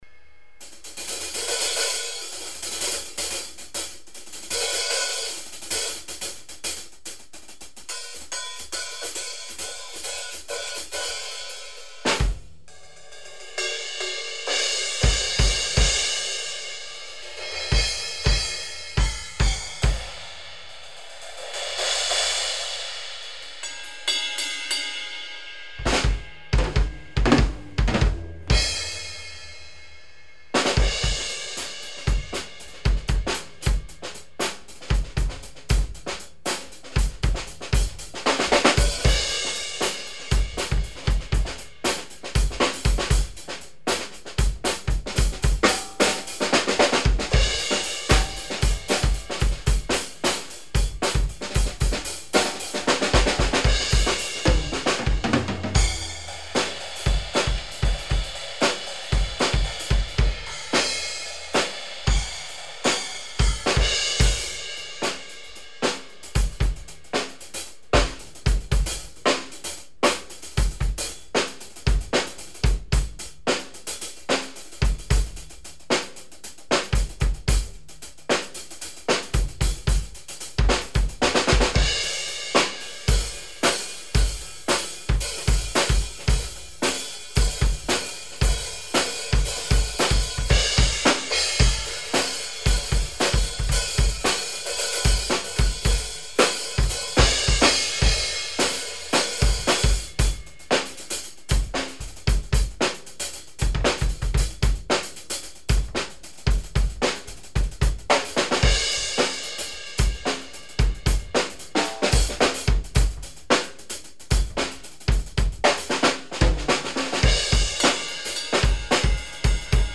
Voila j'ai enregistré vite fait un truc, seullement comme j'ai changer la dispo de ma batterie, j'ai pas trop de reperes pour l'enregistrement et vu que j'ai qu'un seul micro ... en plus j'avais oublié de remonter la bague des graves :p donc il y a pas mal d'equalo sur le bas du spectre et la caisse claire ressort pas vraiment (elle était cachée donc bon :( ) m'enfin y'a les cymbales :) j'ai rajouté un truc plus rock avec de la ride a la fin, mais j'étais pas motivé rock ce soir :)
La Xs20 est un peu métalique quand meme ca se sent a l'enregistrement mais bon pour l'instant je la supporte en jeu :p
Edit : tiens mon tom bass fait un drole de son, il faut que je le regle ...
tes crash sonnent bien graves a mon gout, y a pas a tortiller je prefere la byzance largement; la XS20 sonne effectivement un brin metallique, mais elle a des harmoniques interessantes . c'est moi qui reve ou tu la joues quand meme moins que la byzance?
cymb.mp3